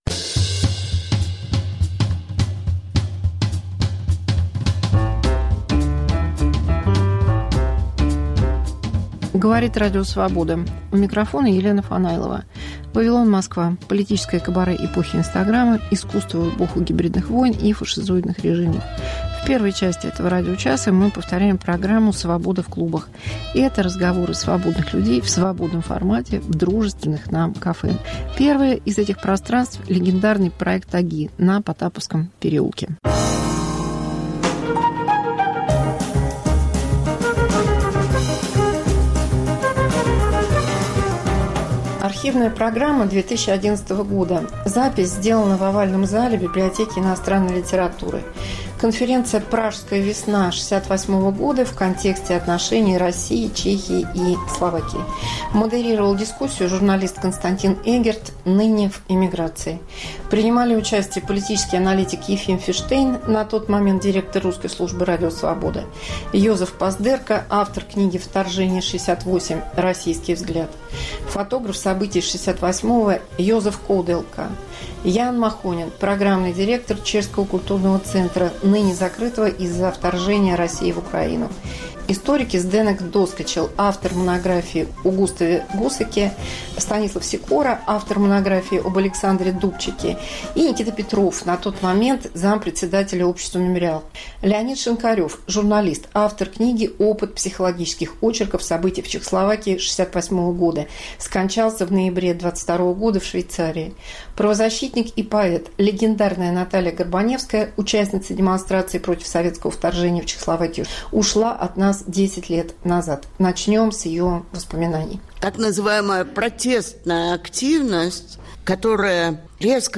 Чтение с комментариями